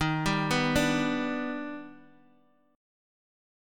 Eb+M7 Chord
Listen to Eb+M7 strummed